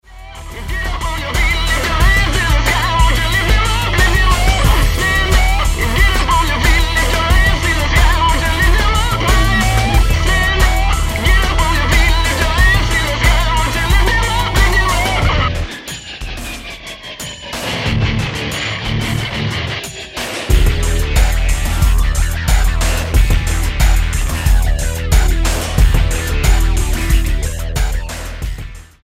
Rock Album
Style: Rock